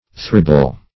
Search Result for " thribble" : The Collaborative International Dictionary of English v.0.48: Thribble \Thrib"ble\ (thr[i^]b"b'l), a. Triple; treble; threefold.